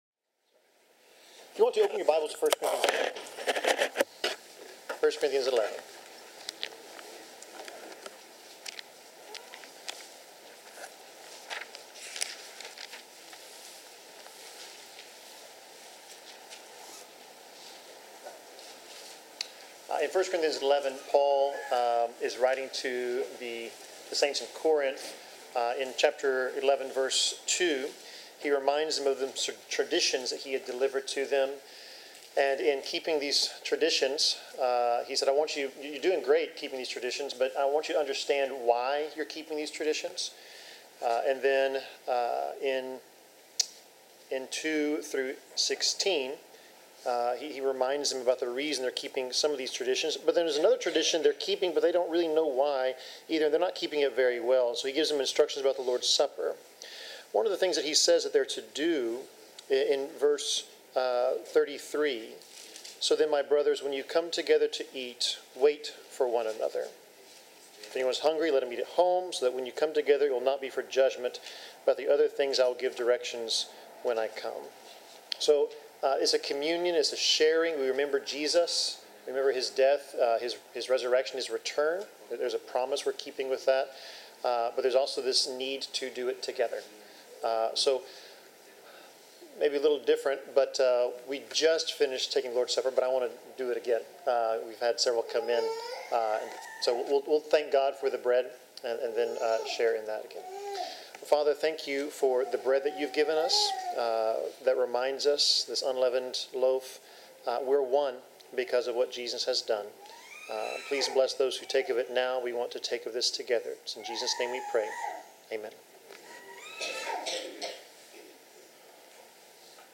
Passage: Acts 8:1-25 Service Type: Sermon